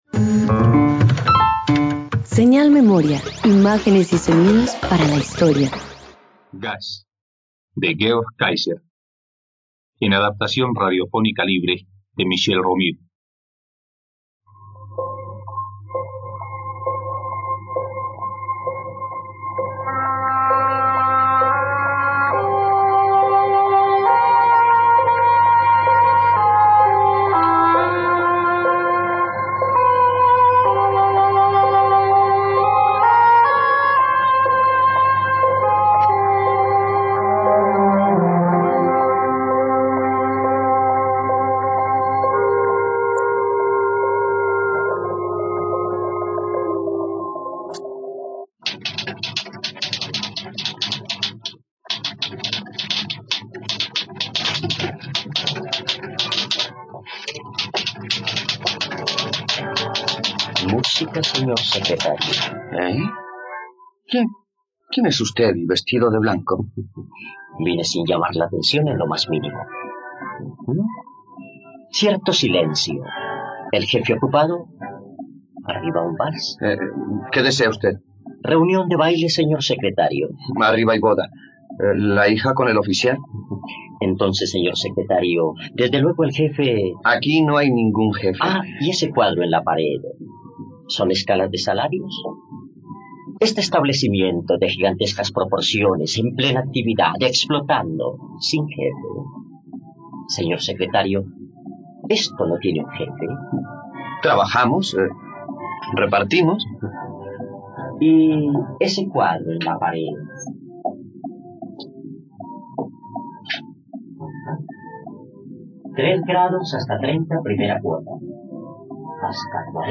..Radioteatro. Escucha ‘Gas’, una adaptación radiofónica basada en la obra de Friedrich Georg Kaiser.